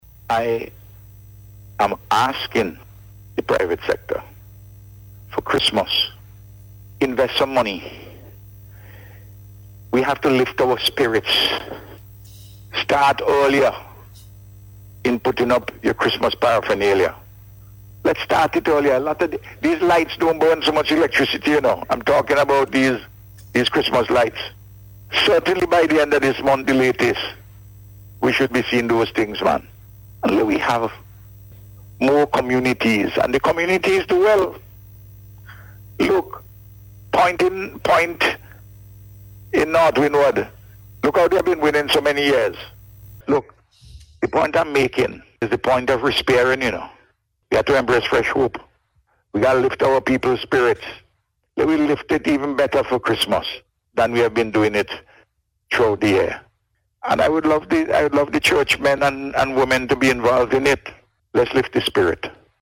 Speaking on Radio on Sunday, the Prime Minister said just a few business owners in Kingstown decorated their businesses this year.